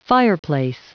Prononciation du mot fireplace en anglais (fichier audio)
Prononciation du mot : fireplace